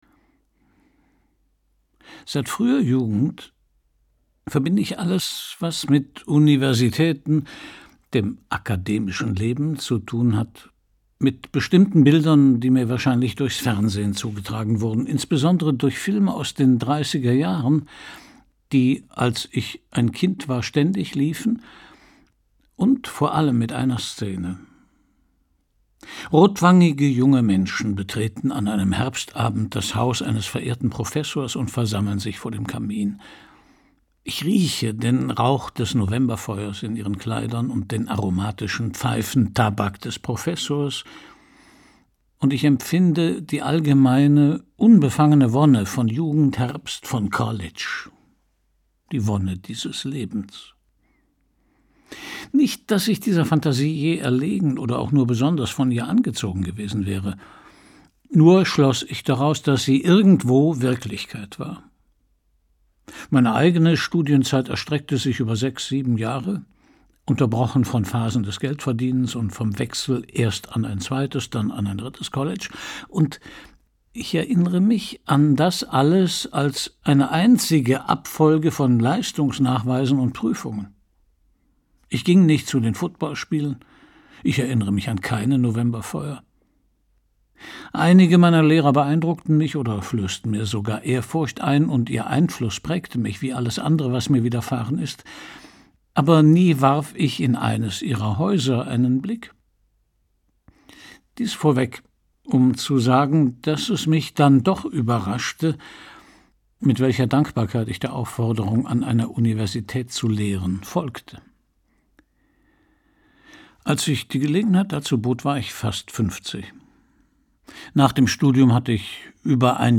Gekürzt Autorisierte, d.h. von Autor:innen und / oder Verlagen freigegebene, bearbeitete Fassung.
Der Name der Welt Gelesen von: Christian Brückner